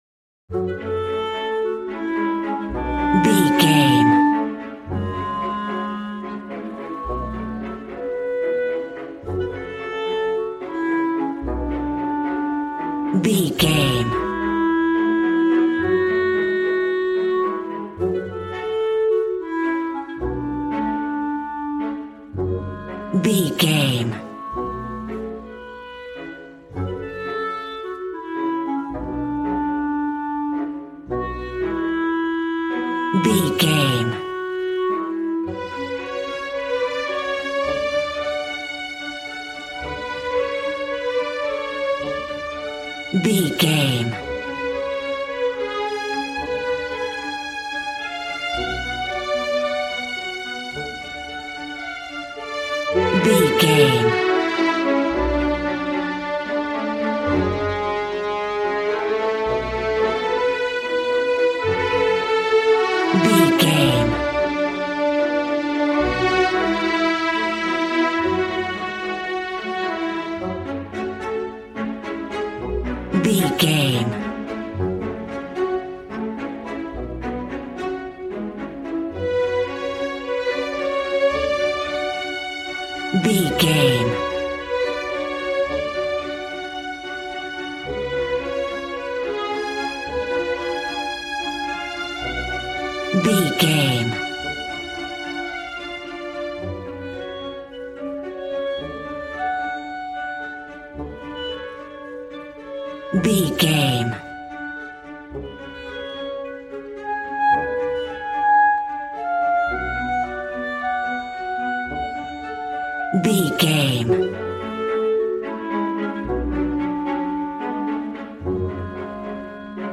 A warm and stunning piece of playful classical music.
Regal and romantic, a classy piece of classical music.
Aeolian/Minor
G♭
regal
piano
violin
strings